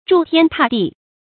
柱天踏地 zhù tiān tà dì
柱天踏地发音
成语注音ㄓㄨˋ ㄊㄧㄢ ㄊㄚˋ ㄉㄧˋ